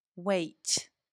IPA/weɪt/